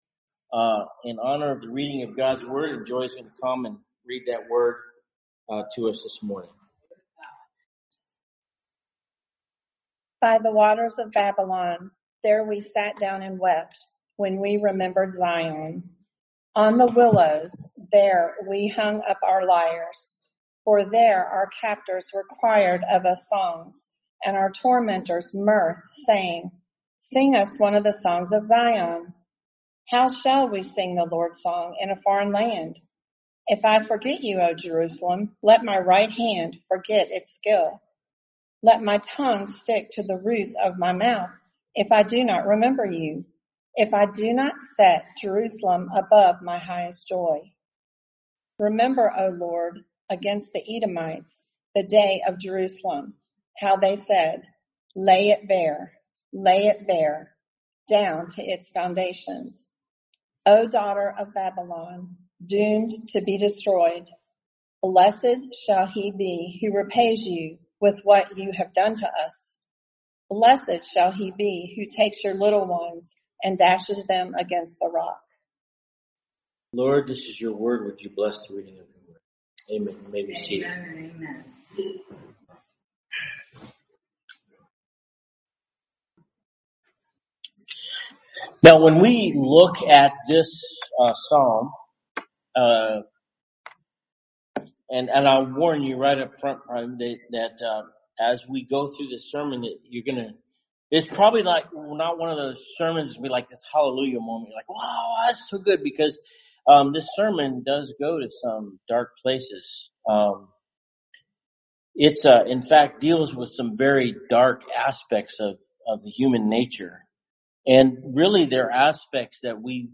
Summer Psalms Passage: Psalm 137 Service Type: Sunday Morning Topics